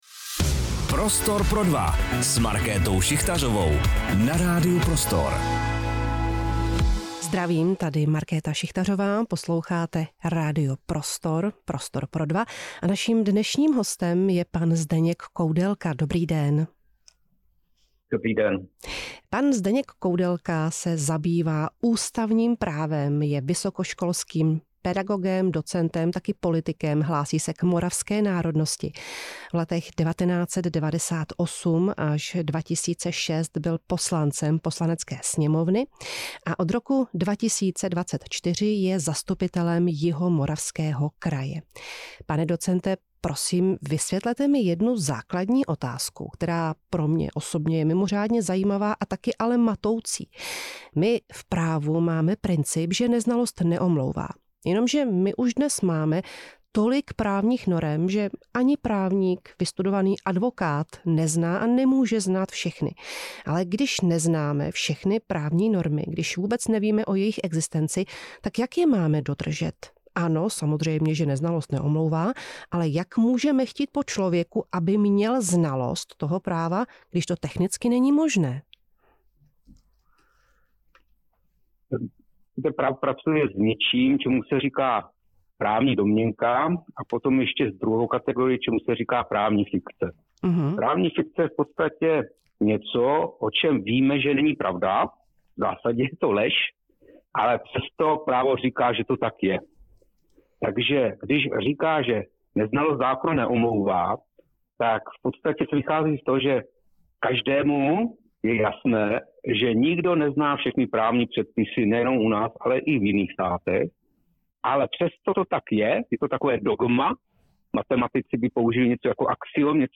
O tom si v Prostoru pro dva povídala Markéta Šichtařová s ústavním právníkem Zdeňkem Koudelkou.